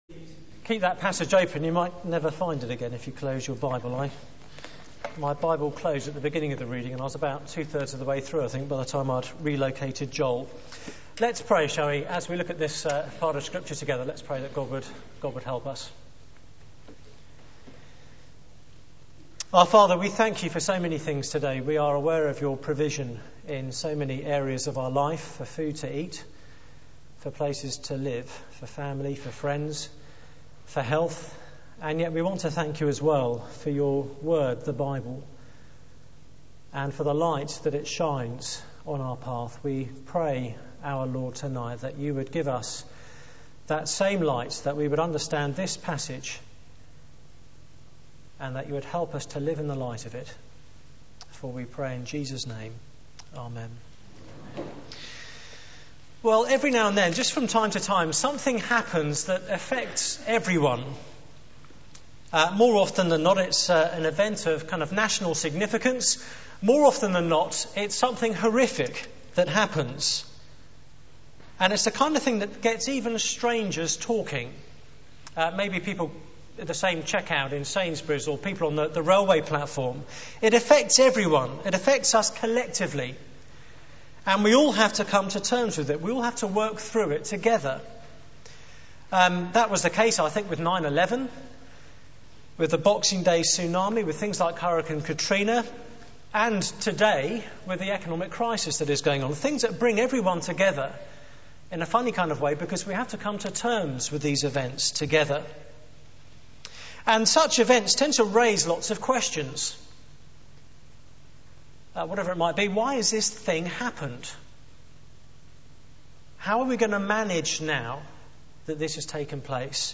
Media for 9:15am Service on Sun 05th Oct 2008 18:30 Speaker: Passage: Joel 1 Series: A Day is Coming Theme: The Day the Locusts Came Sermon slide Open Search the media library There are recordings here going back several years.